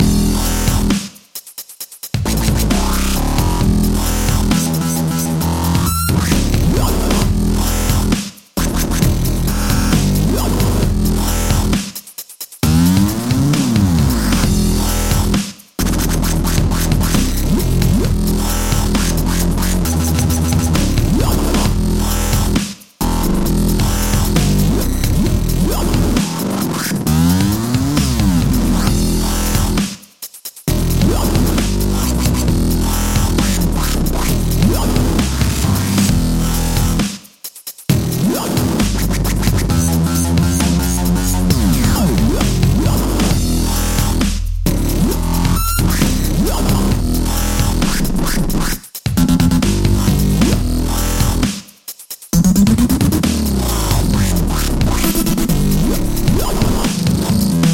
Here is an example of the kind of sound you can expect to end up with at the end of this series: